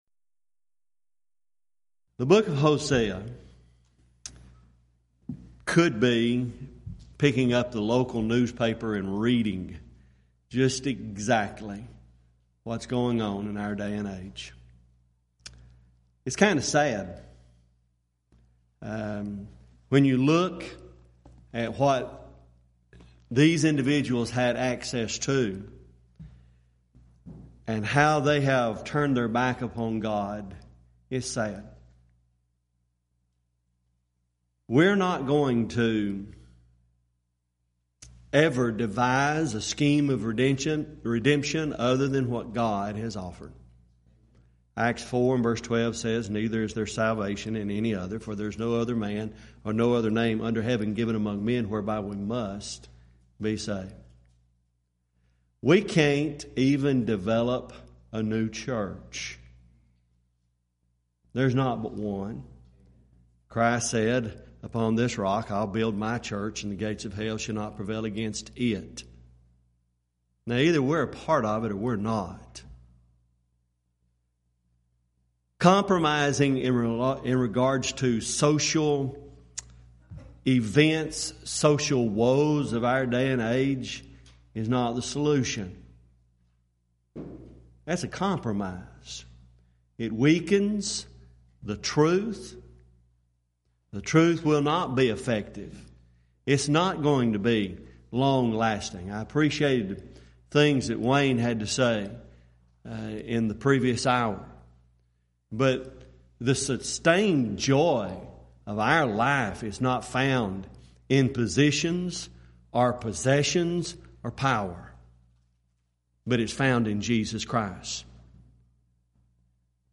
Event: 12th Annual Schertz Lectures Theme/Title: Studies in the Minor Prophets
lecture